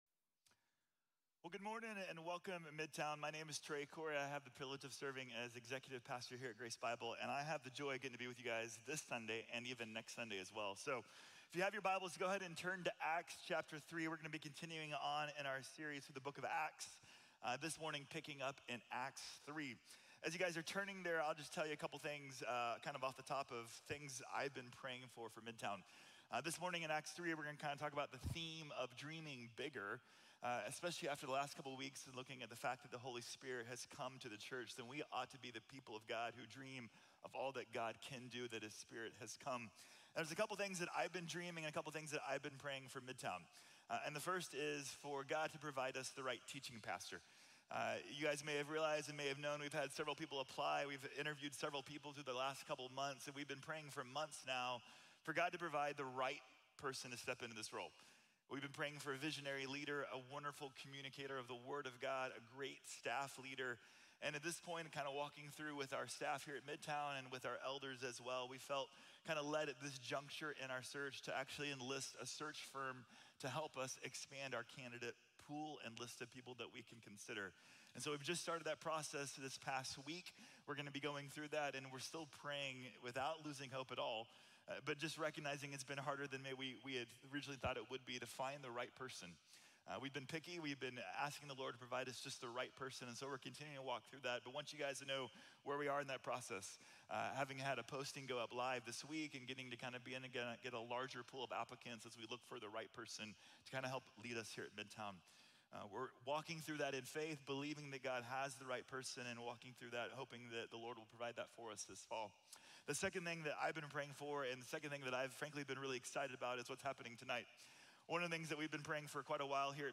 Dreaming Bigger | Sermon | Grace Bible Church